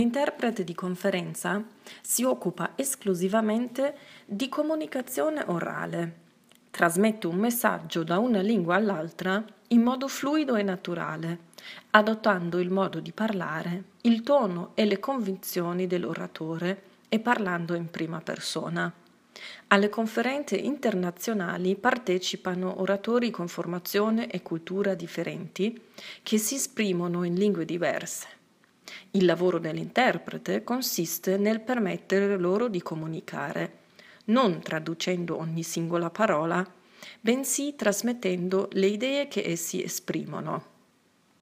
MEINE STIMME
Italienisch